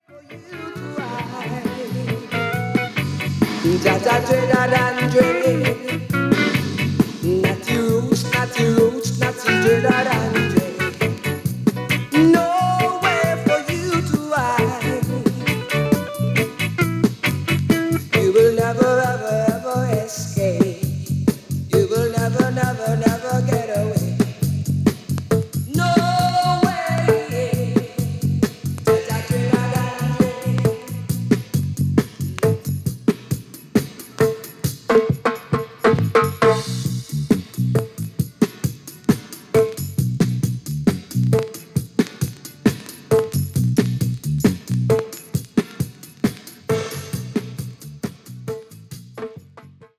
そうそうA-4にキズあります。